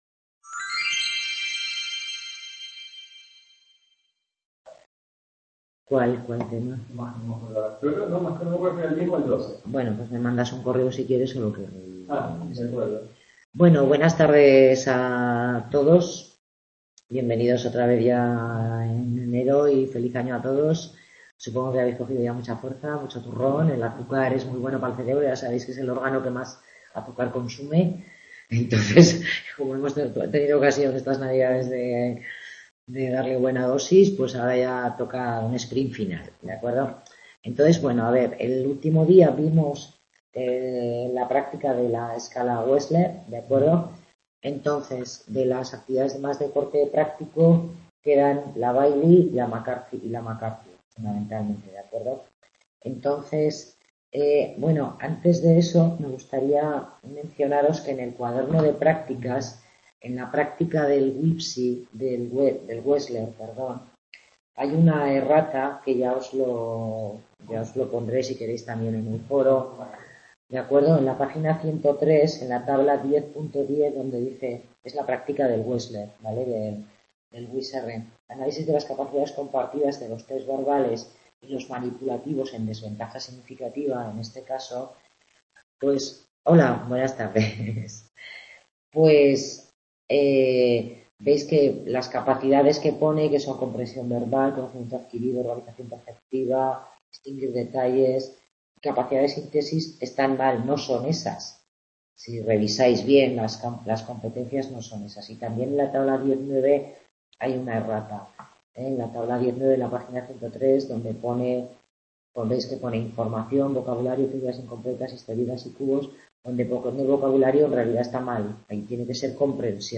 Tutoría grupal en la que se revisa la aplicación de las escalas Bayley de Desarrollo y la McCarthy